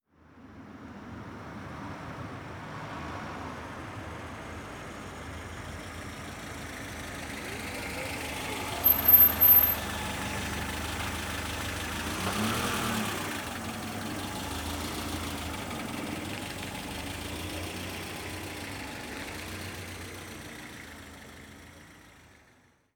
Coche diesel aparcando
diesel
Sonidos: Transportes